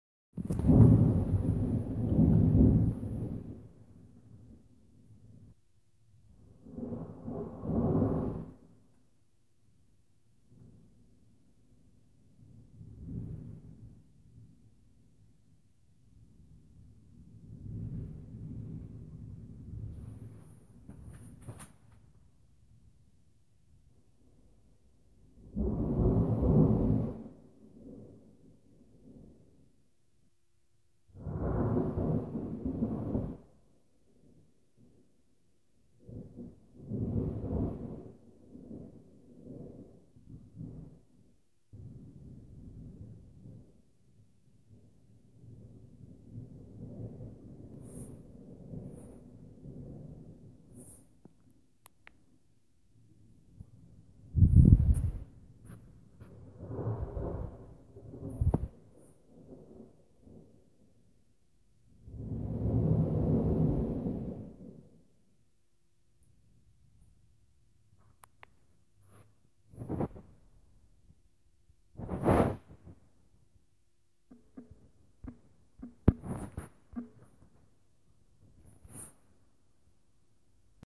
雷暴和暴雨
你也可以听听这个盲人单位之间的风！
Tag: 雷暴 罢工 风暴 天气 隆隆声 淋浴器 防雷 防雨 雷暴 闪光 滚雷声 雷声 性质 现场记录 下雨